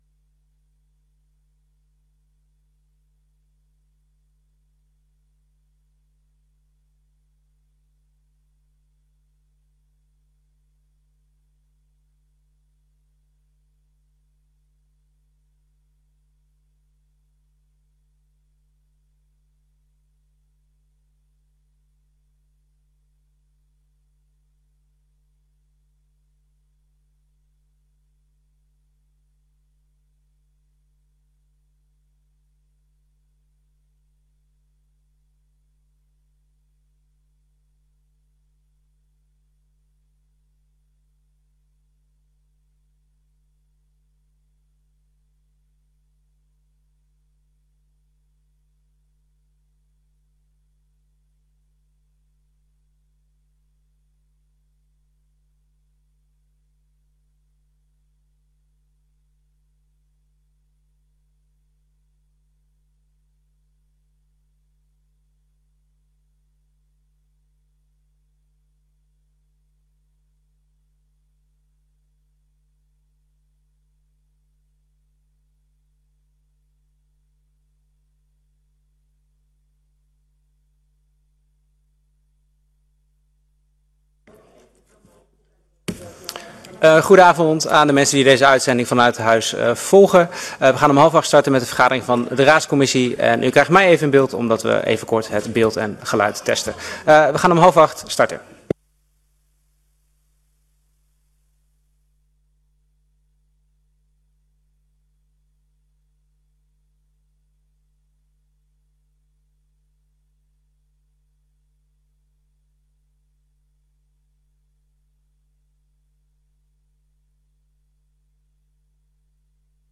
Locatie: Raadzaal Voorzitter: M.J. Holterman